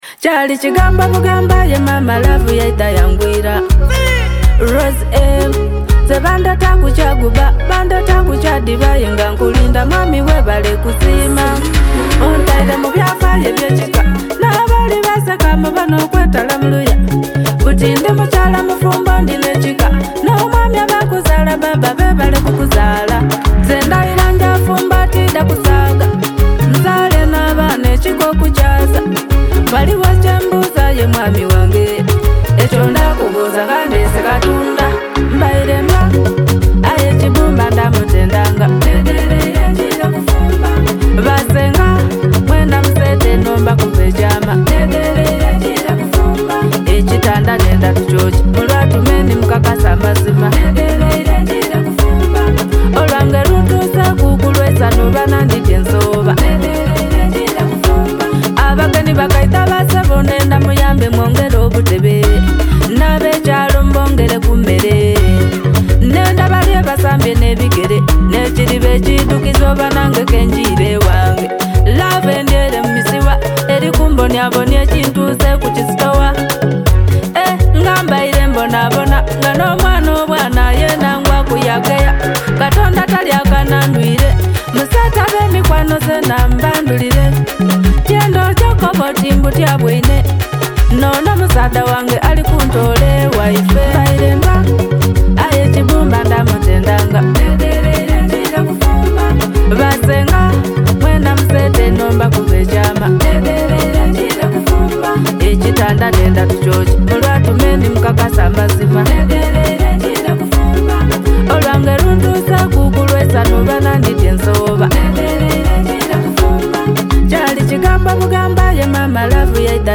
Genre: Band Music